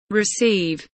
receive kelimesinin anlamı, resimli anlatımı ve sesli okunuşu